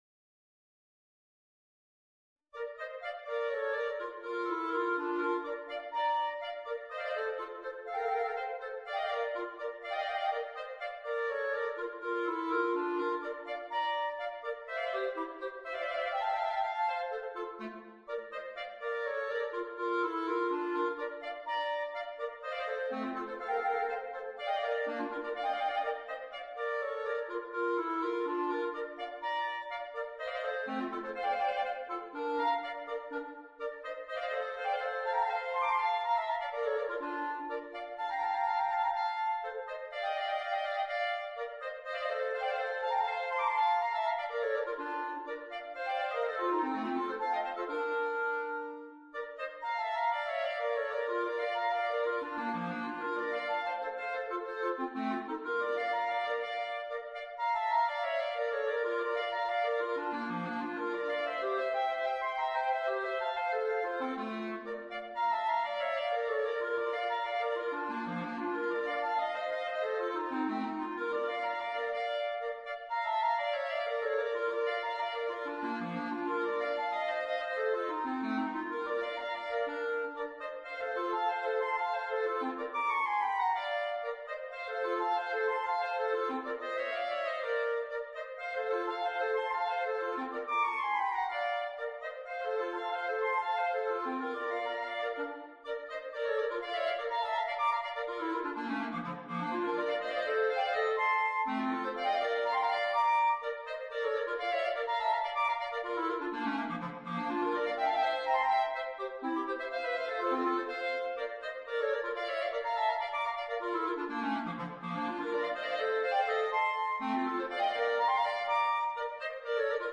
Per 2 clarinetti